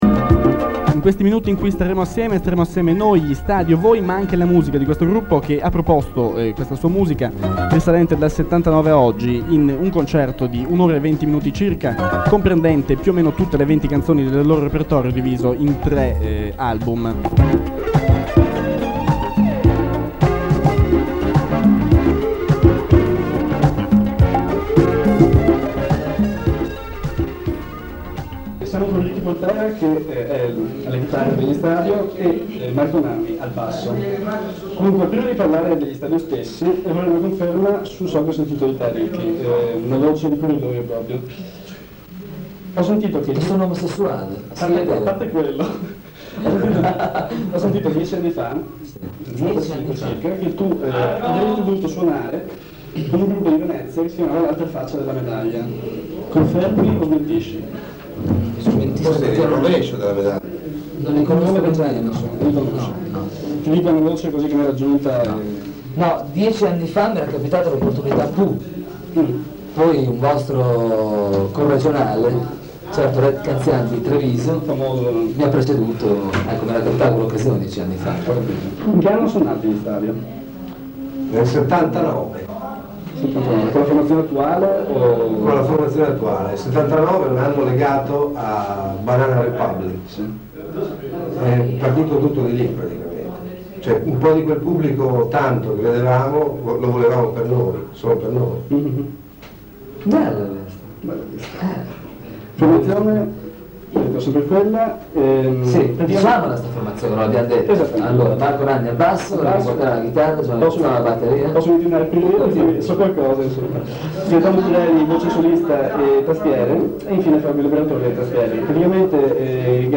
Quella che ho ripescato è un’intervista radiofonica di quelli che si possono definire “i loro inizi”, le prime occasioni di poter fare un tour e riempire (intanto) i teatri di tutta Italia.
L’intervista raccolta per Radio Studio 104 fu mandata in onda all’interno di un apposito Speciale Stadio ed intervallata dalle canzoni del gruppo. L’audio che si è salvato risente dell’usura degli anni passati in un umido garage.